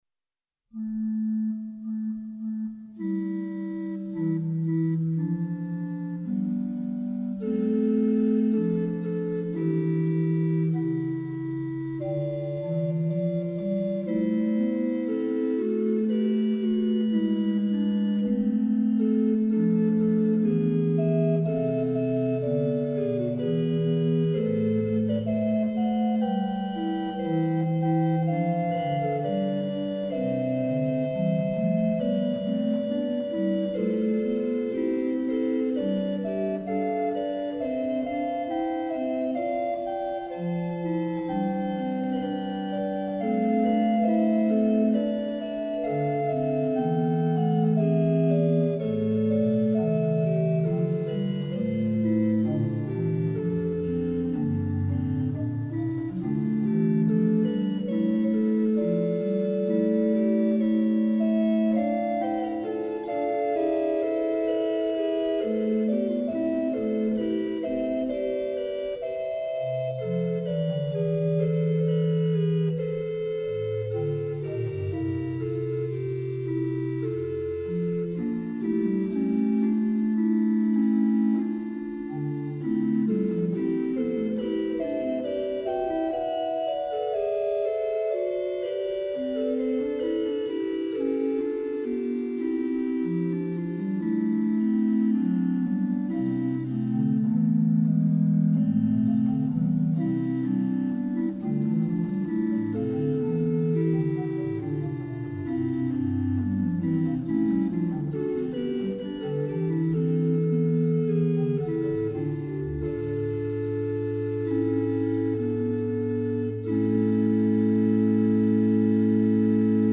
Stopped Diapason